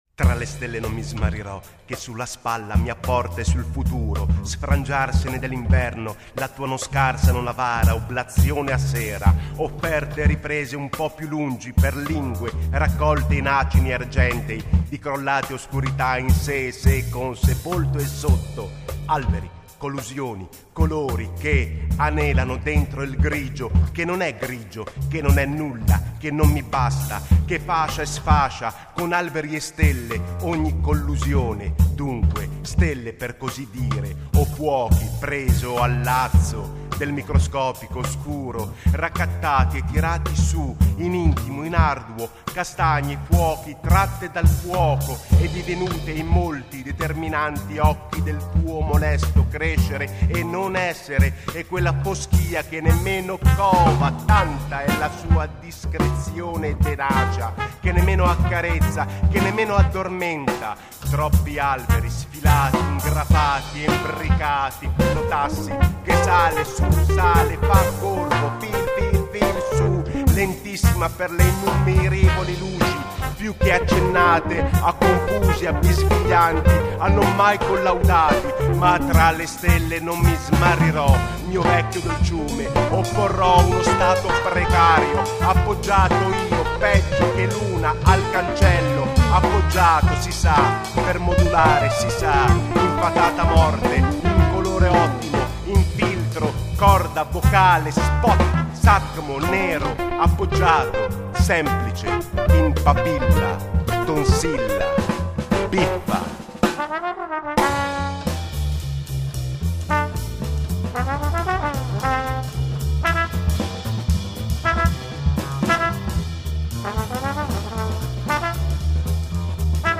contrabbasso